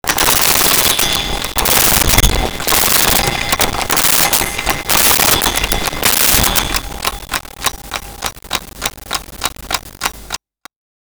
Clock Winding 1
clock-winding-1.wav